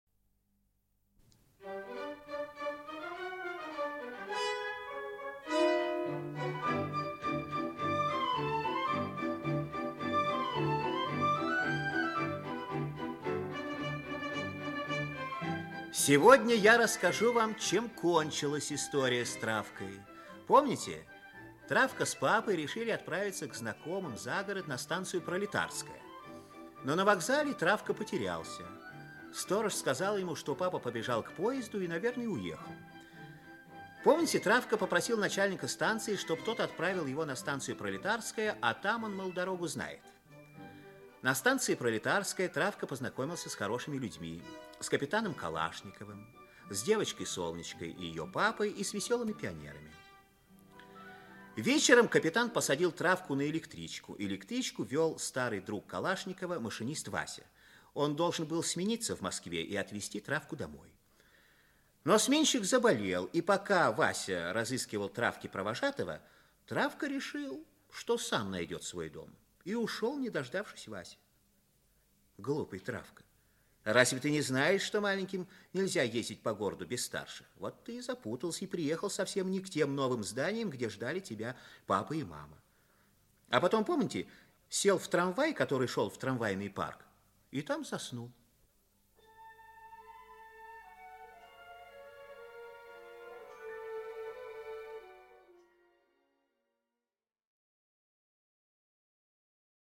Аудиокнига Приключения Травки. Часть 3 | Библиотека аудиокниг
Часть 3 Автор Сергей Григорьевич Розанов Читает аудиокнигу Актерский коллектив.